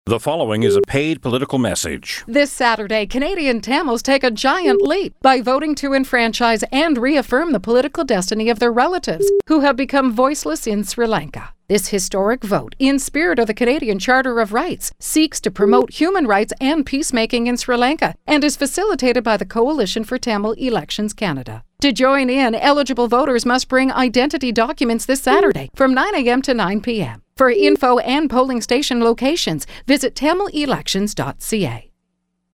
Voice: A commercial announcement in 680 News